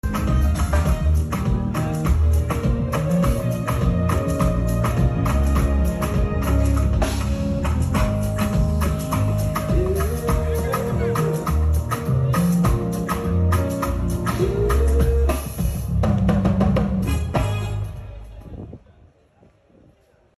concert sound check